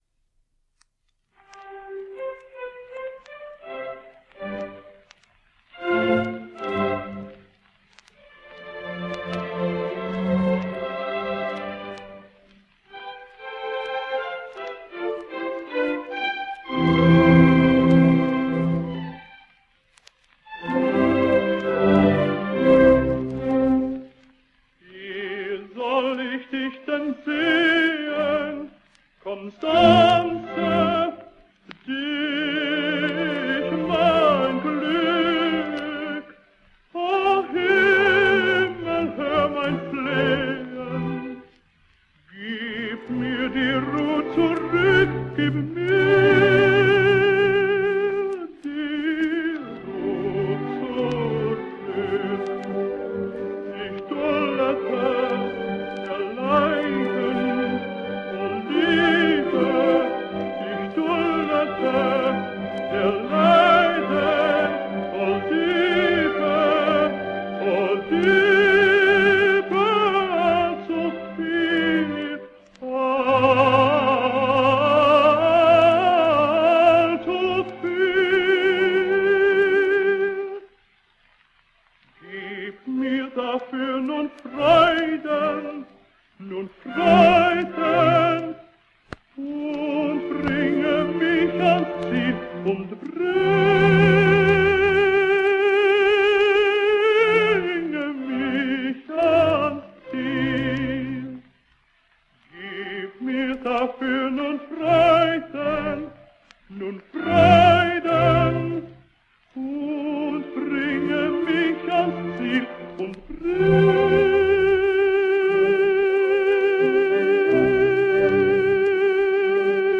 Danish tenor.